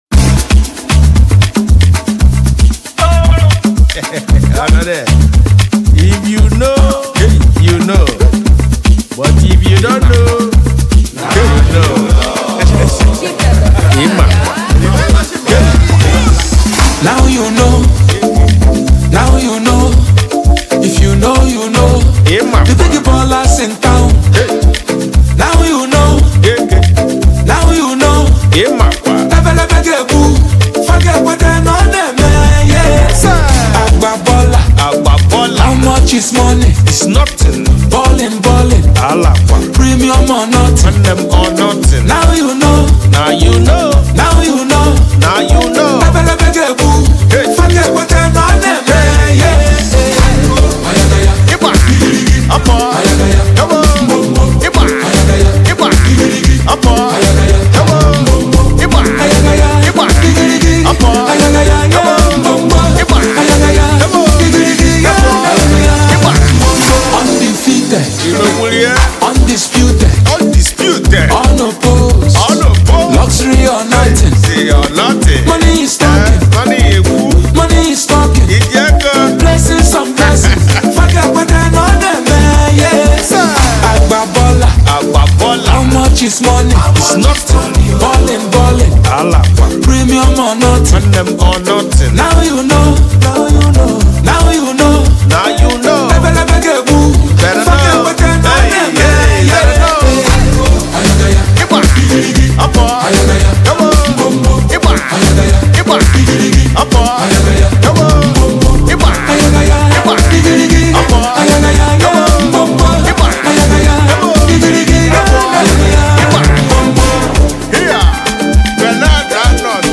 is a catchy and upbeat song that celebrates the good life